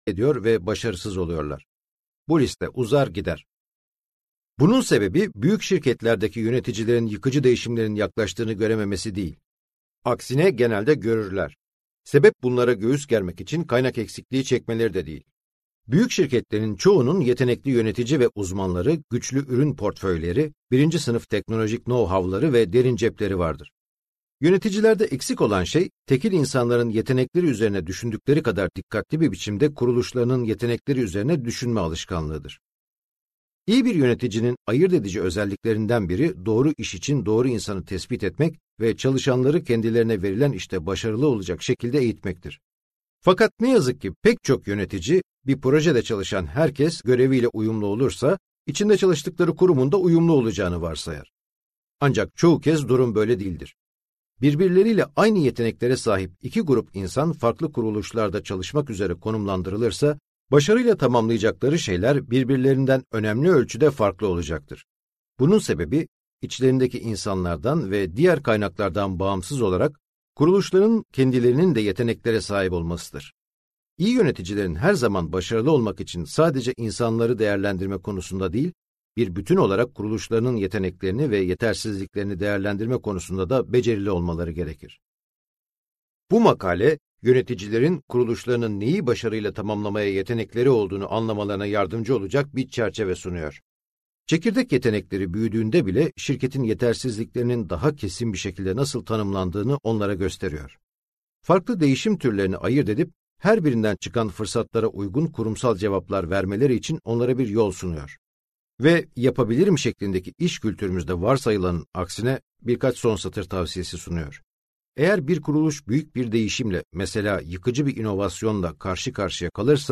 Yıkıcı Değişimin Meydan Okumasını Göğüslemek - Seslenen Kitap